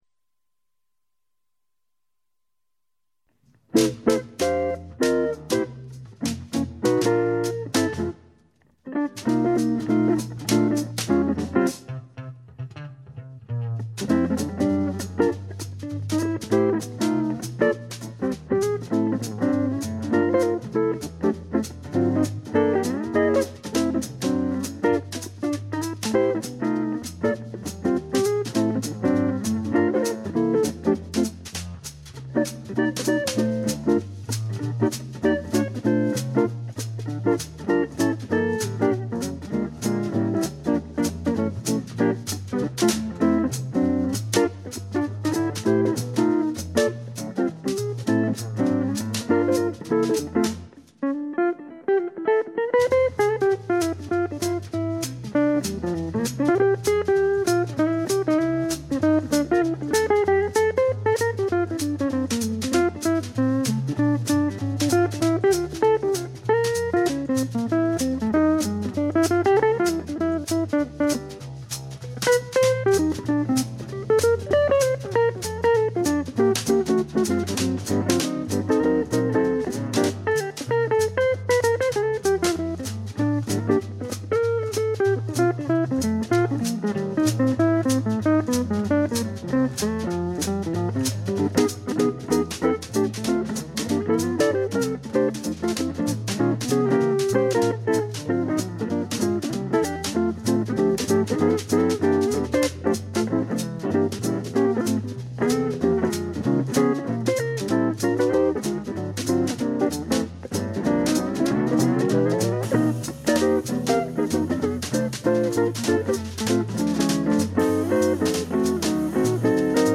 Gepflegter Gitarren-Jazz.
Im Trio mit Kontrabass und Schlagzeug